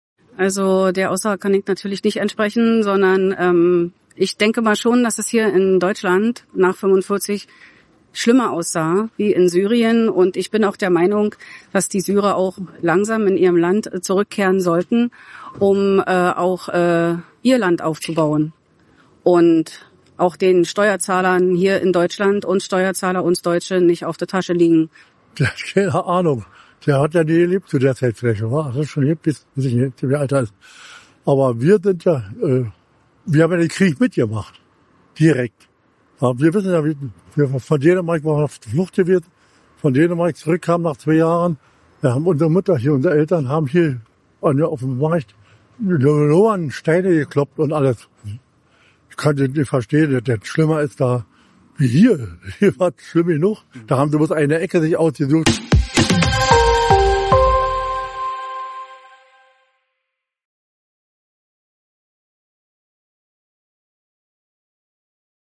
AUF1 hat sich in Brandenburg umgehört.